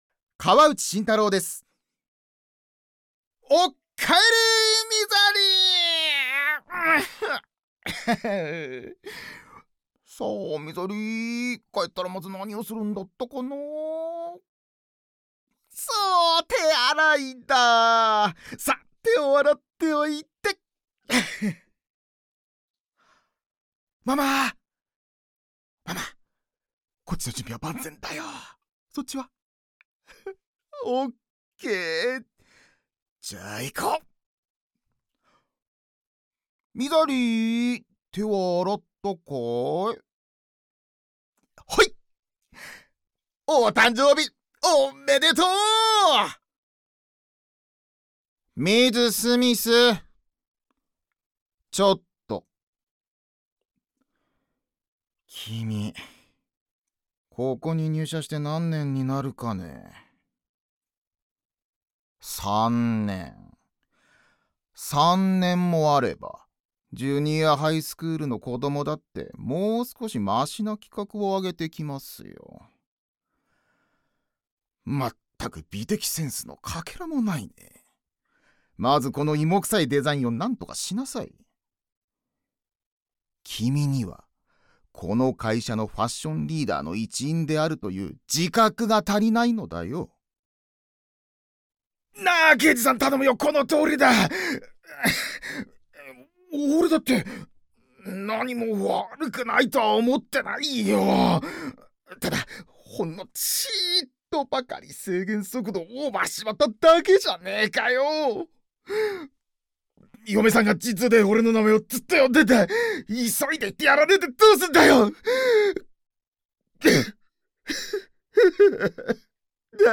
セリフ